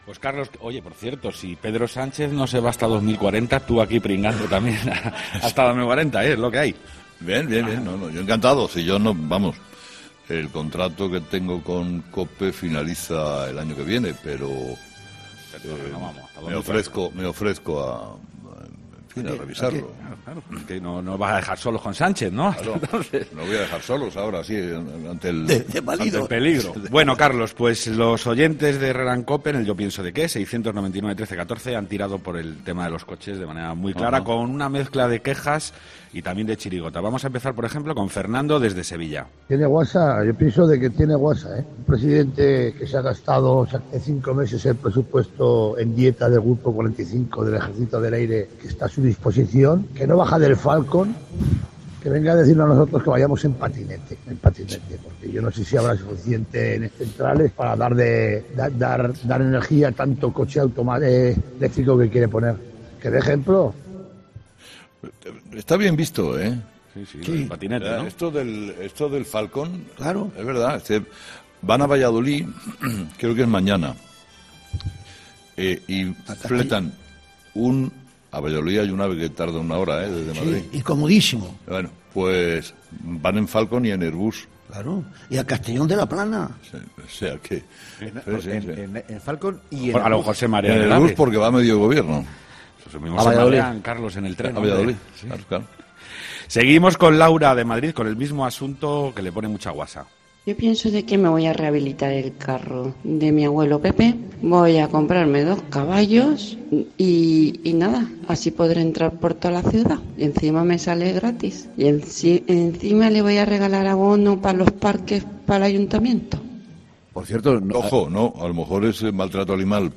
Más bien nos ha contado que él estuvo hace unos años en la bodega de Emilio Moro, lugar en el que hoy se está haciendo el programa, y ha asegurado que es " una bodega aseada, limpia y con un muy buen vino ". La tertulia de los oyentes se emite de lunes a jueves de 9:30 a 10 y recoge los mejores comentarios de los seguidores del programa de Carlos Herrera.